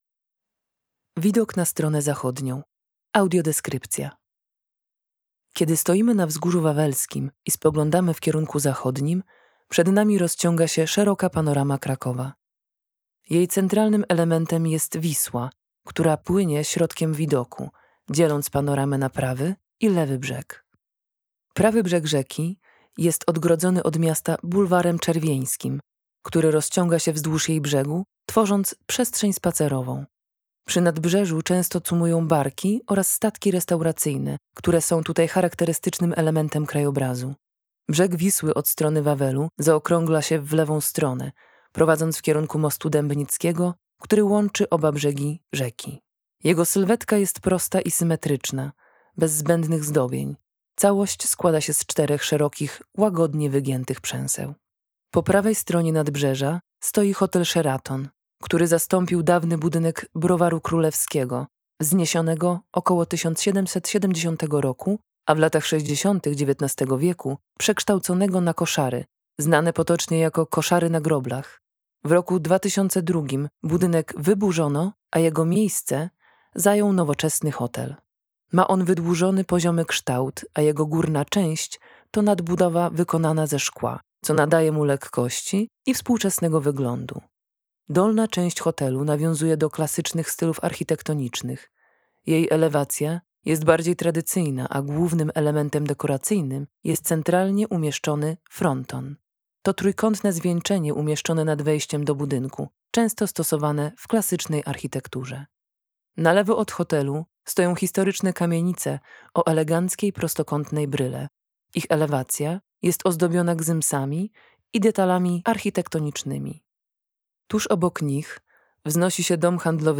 Audiodeskrypcje widoków z Wawelu - ZACHÓD